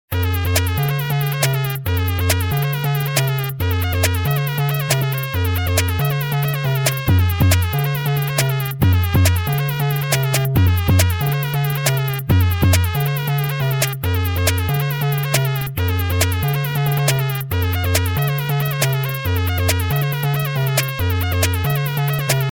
sick-beat-made-with-Voicemod-technology-timestrech-2x.mp3